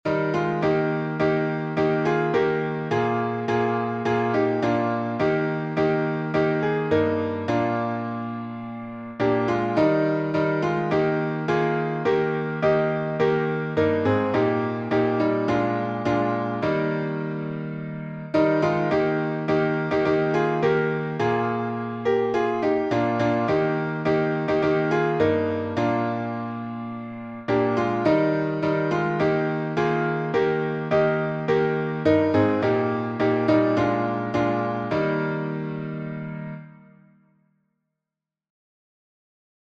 #4135: On Jordan's Stormy Banks I Stand — E flat major | Mobile Hymns
Tune: PROMISED LAND, an American folk hymn arranged by Rigdon M. McIntosh (1836-1899), 1895. Key signature: E flat major (3 flats) Time signature: 4/4 Meter: 8.6.8.6.(C.M.) with Refrain Public Domain 1.